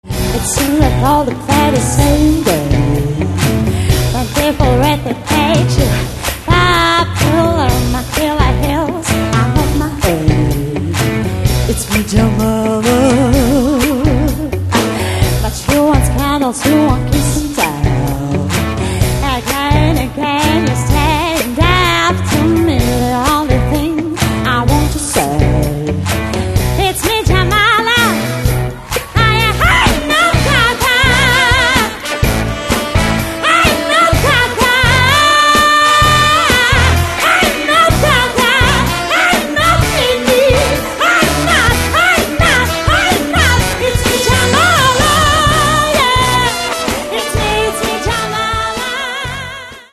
Catalogue -> Modern Pop